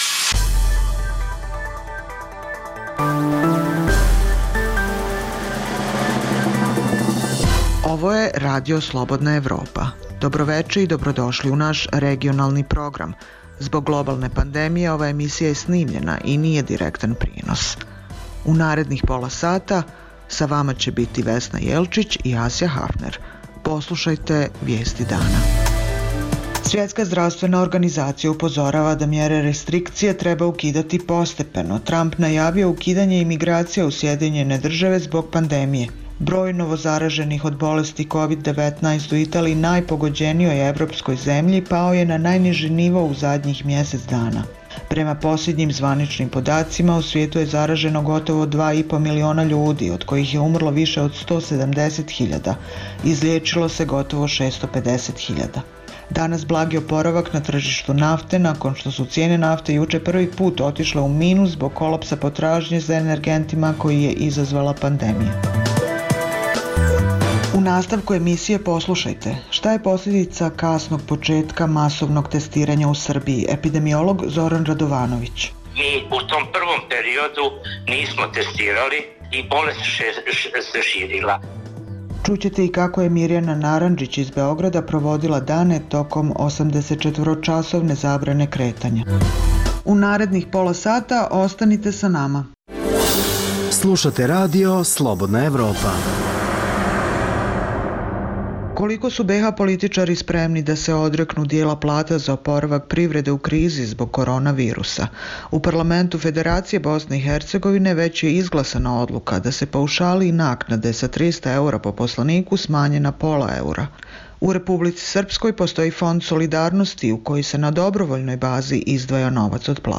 Zbog globalne pandemije, ova je emisija unapred snimljena i nije direktan prenos Svetska zdravstvena organizacija upozorava da mere restrikcije treba ukidati postepeno, Tramp najavio ukidanje imigracija u SAD zbog pandemije. Prema poslednjim zvaničnim podacima u svijetu je zaraženo gotovo 2,5 miliona ljudi, od kojih je umrlo više od 170.000, a izlečilo se gotovo 650.000. Danas blagi oporavak na tržištu nafte nakon što su cene nafte juče prvi put otišle u minus zbog kolapsa potražnje za energentima koji je izazvala pandemija